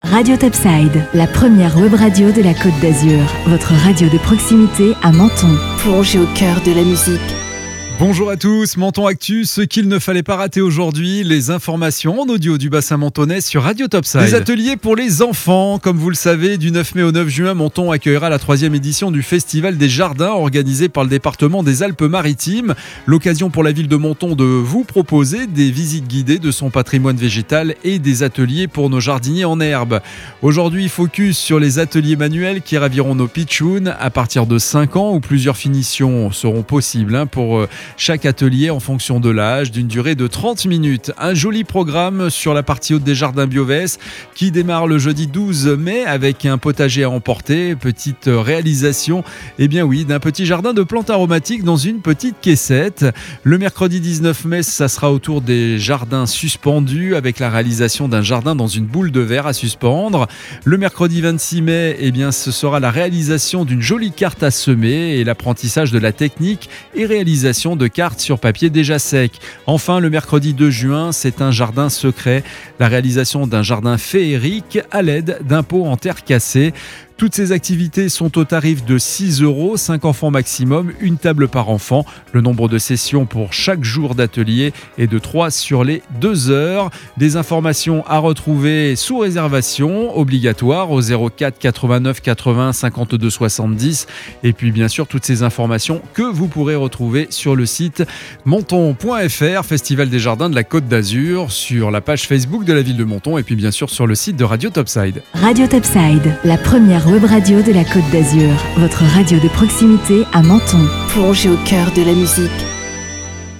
Menton Actu - Le flash info du mercredi 05 mai 2021